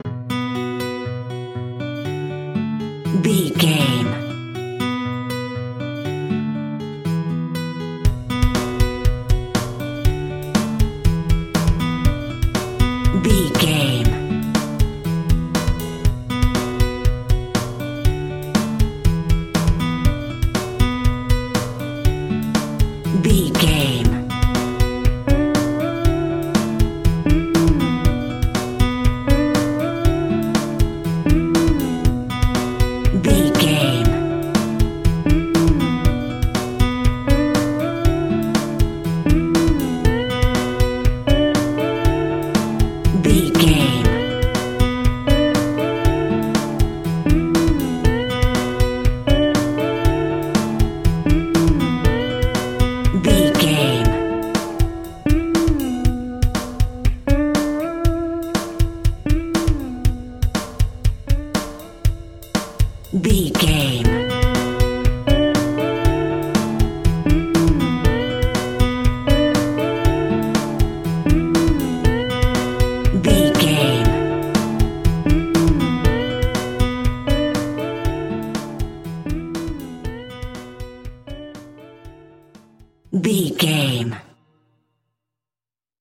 Uplifting
Dorian
Funk
funky
jazz
disco
electric guitar
bass guitar
drums
hammond organ
fender rhodes
percussion